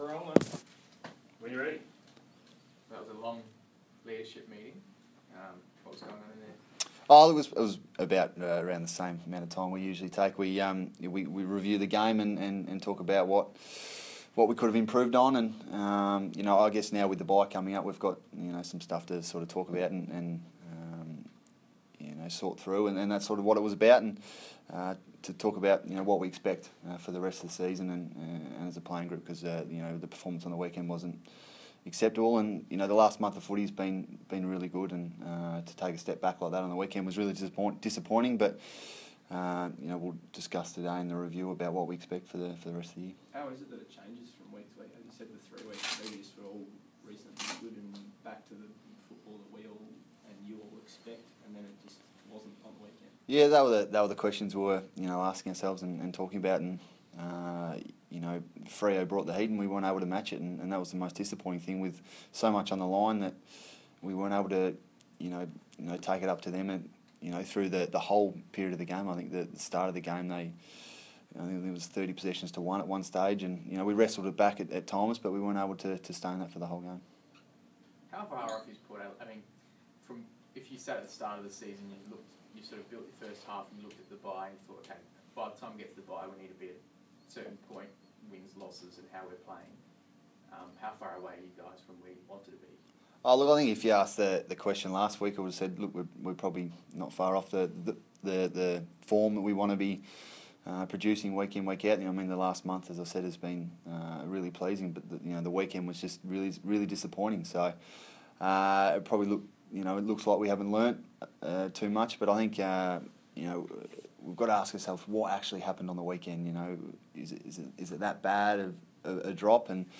Travis Boak press conference - Monday, 20 June, 2016